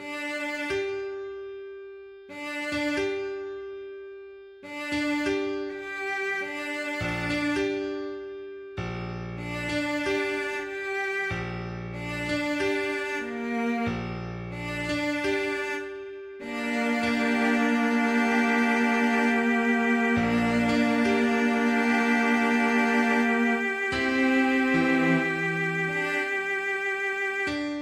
Продукт уже можно генерировать, но сейчас его качество сложно назвать приемлемым, сеть показывает лишь базовое понимание гармонических сочетаний и ритма.